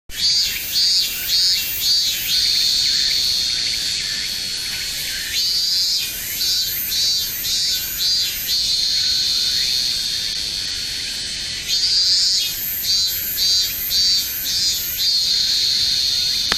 ミンミンとツクツクホウシの大合唱
夜明けとともにセミが鳴き始め、朝日が木々を照らし出す頃、その鳴き声は一段と大きくなりました。午前7時過ぎ、わが家の牛舎周辺の山々からはミンミンゼミとツクツクホウシの大合唱が聞こえてきます。どうやら、私のすぐそばの木にもミンミンがやってきたようです。
2010年8月27日の朝のセミたちの大合唱をお聴きください。